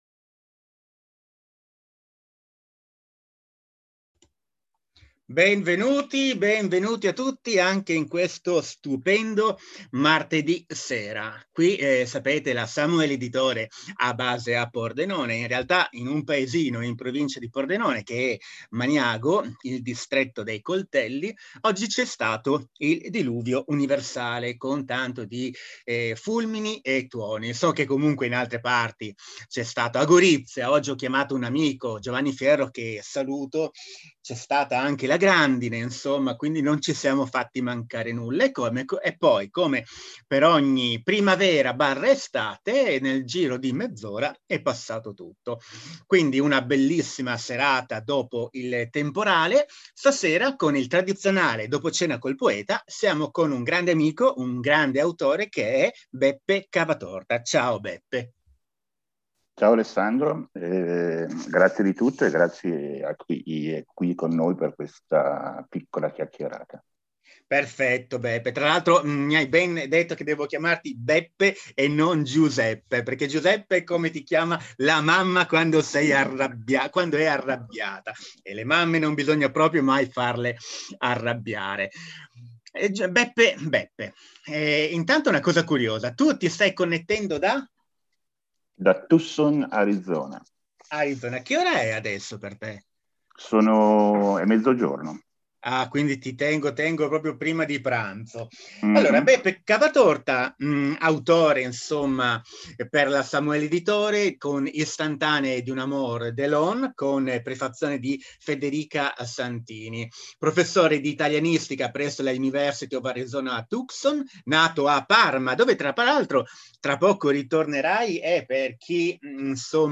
Piccole dirette in solo streaming audio coi poeti della Samuele Editore